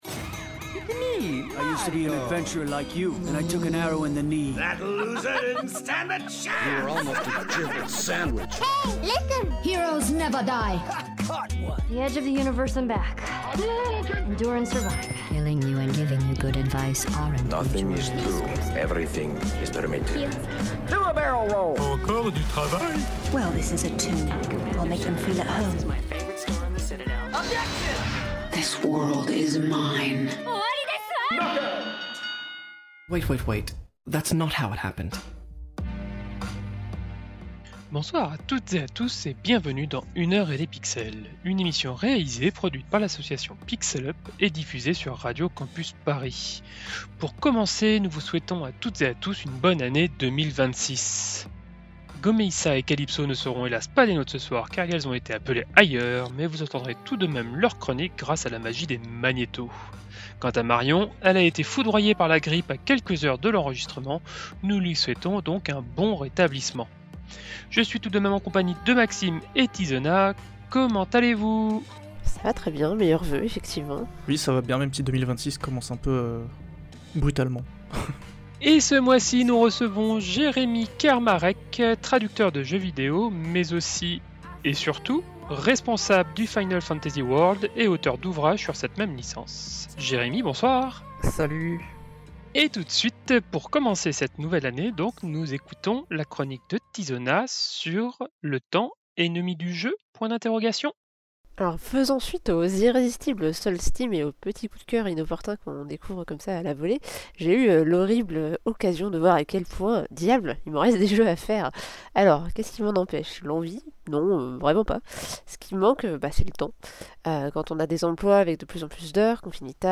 Émission diffusée le 25 janvier 2026 sur Radio Campus Paris.